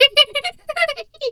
Animal_Impersonations
hyena_laugh_short_02.wav